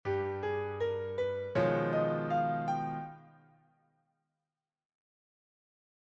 Improvisation Piano Jazz
gamme_alteree.mp3